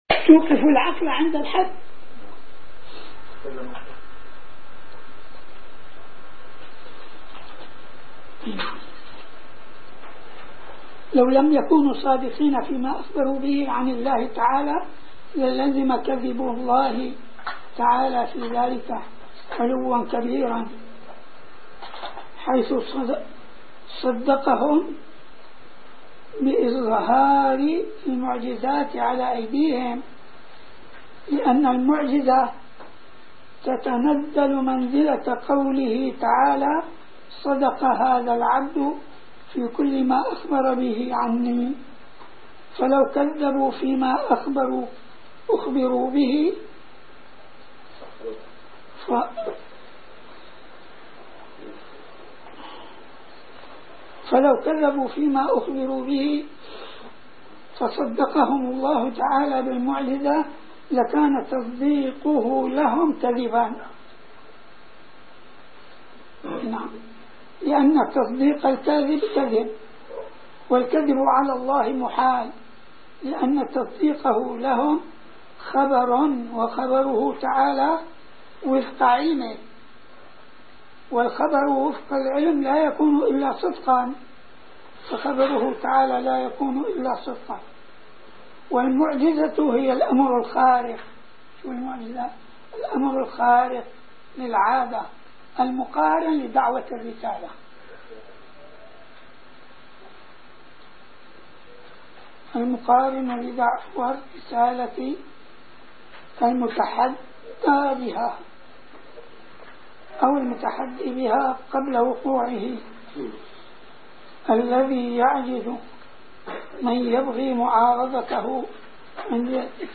- الدروس العلمية - شرح كتاب مفتاح الجنة والوظيفة الشاذلية - الدرس الحادي والعشرون: من الصفحة 201 إلى الصفحة 213